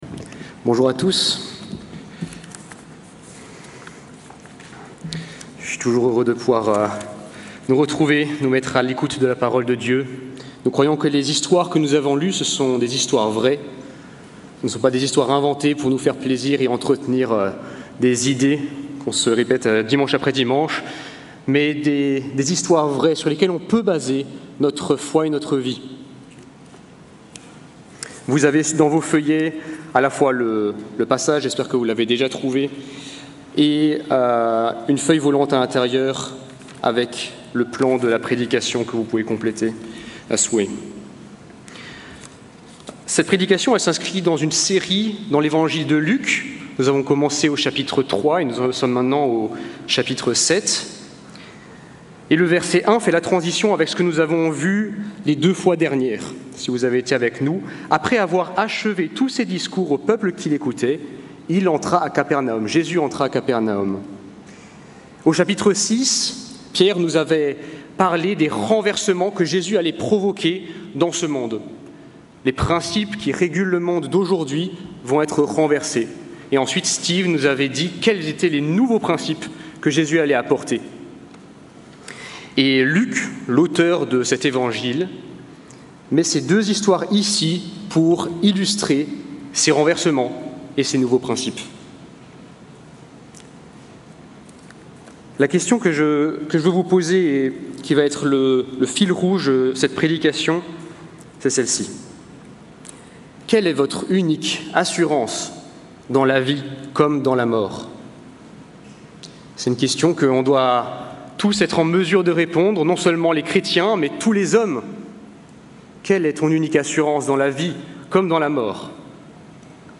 Plan de la prédication : 1.